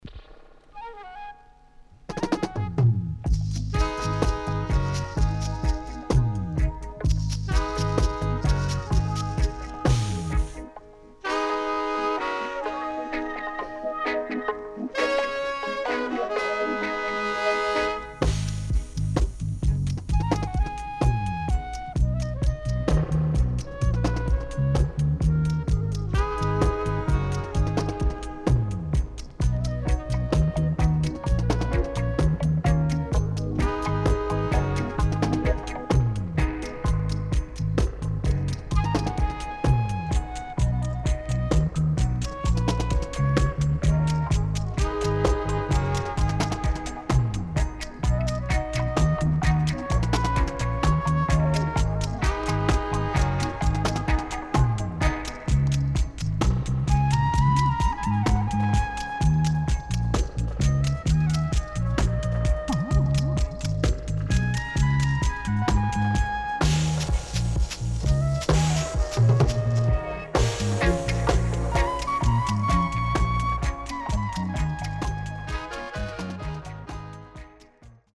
HOME > DUB
SIDE A:少しチリノイズ入りますが良好です。